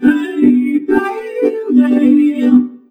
VOXVOCODE2-L.wav